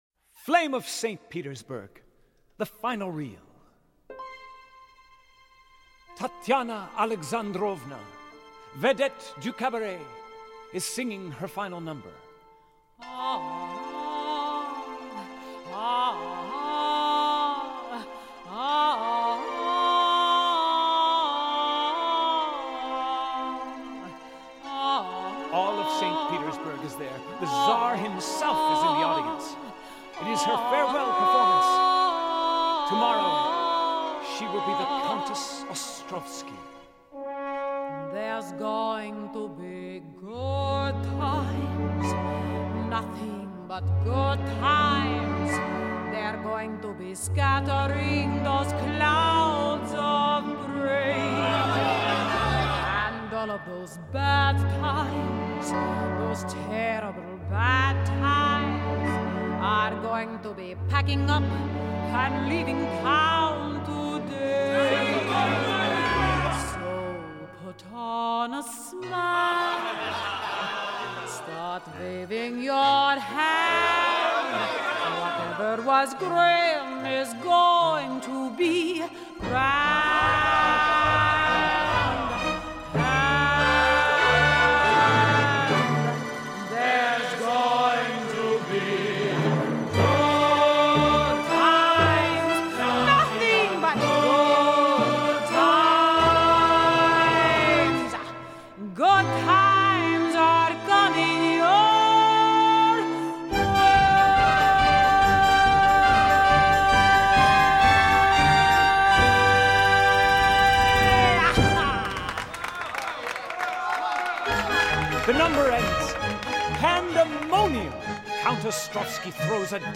it is very theatrical.